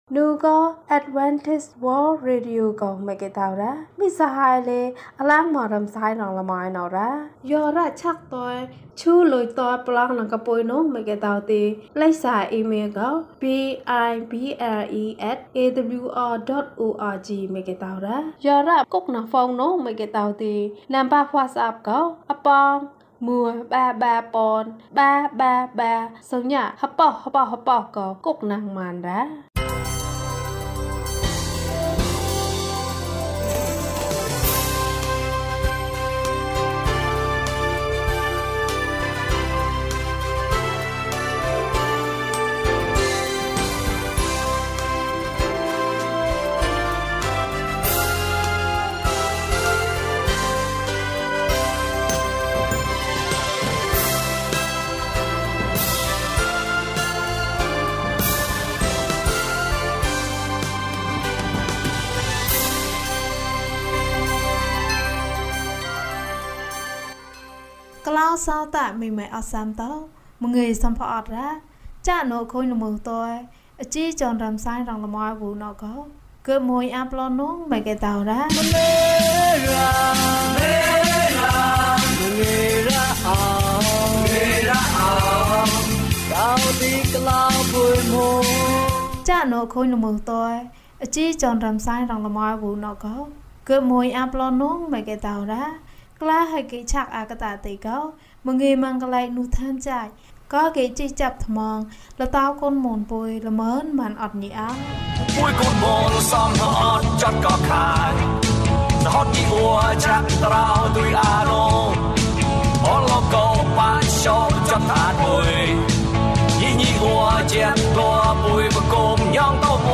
ဘုရားစာအုပ်။ ကျန်းမာခြင်းအကြောင်းအရာ။ ဓမ္မသီချင်း။ တရားဒေသနာ။